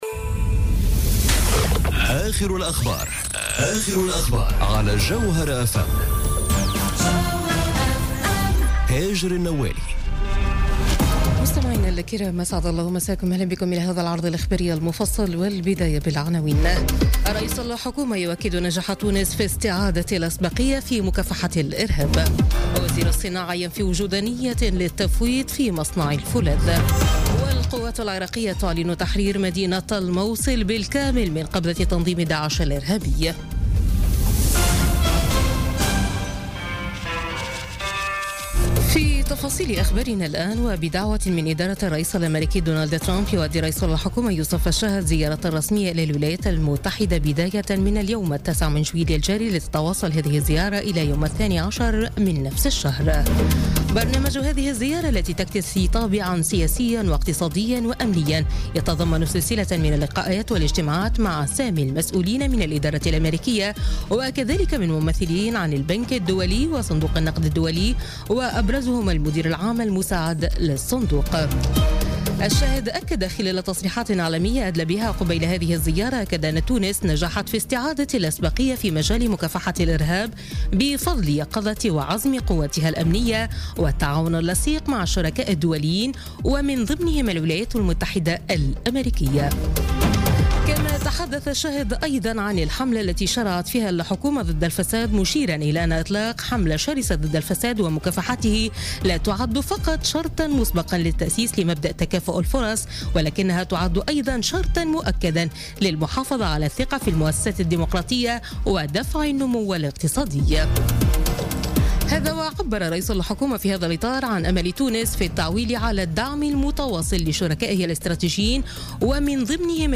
نشرة أخبار منتصف الليل ليوم الأحد 9 جويلية 2017